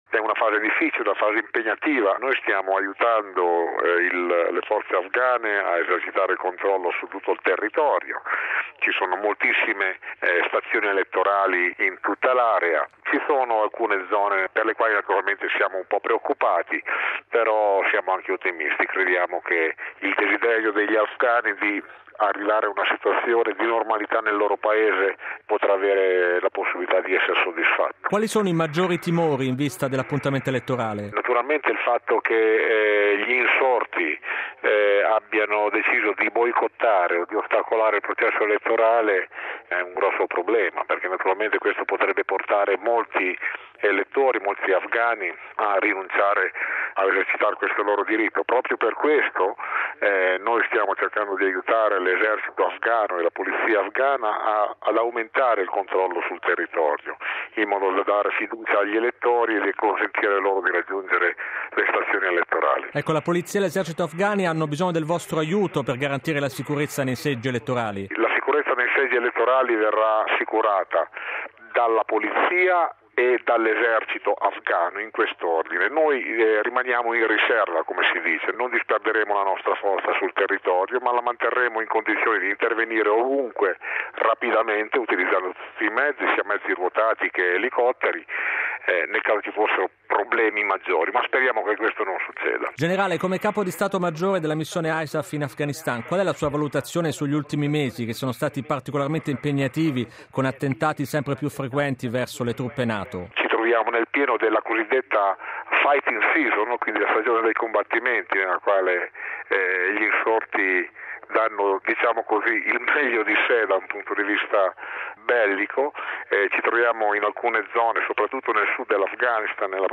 Afghanistan: uccisi 14 civili. Intervista al generale Bertolini